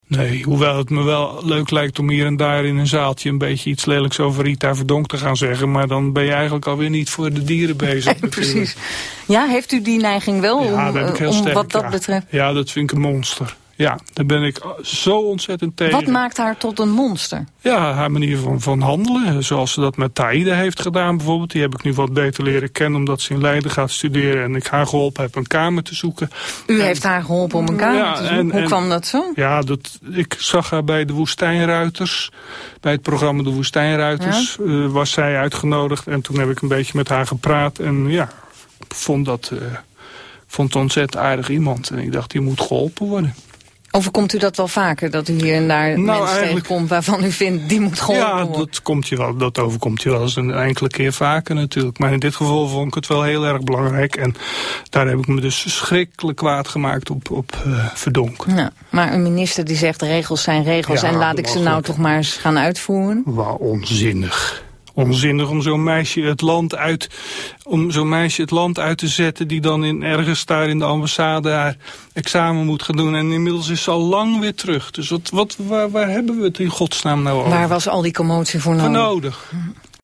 [beluister het hele interview in Kunststof met Maarten ’t Hart hier]